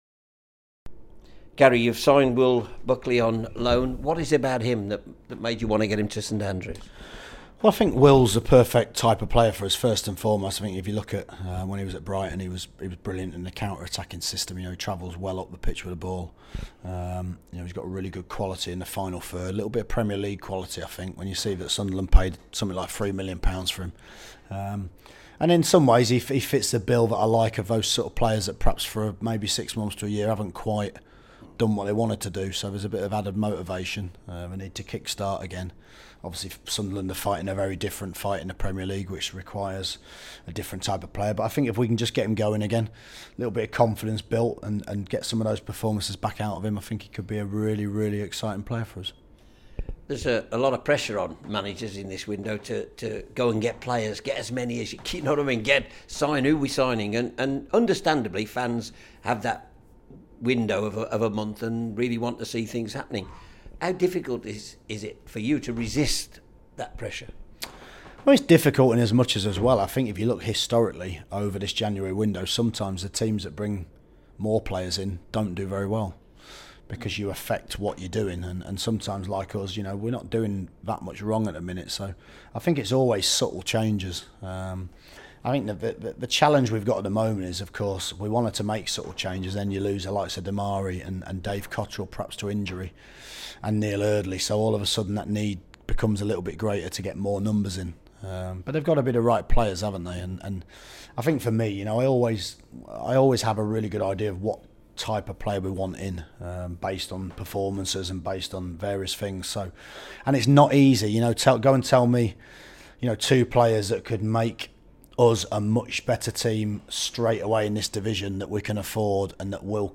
at the club’s Wast Hills training ground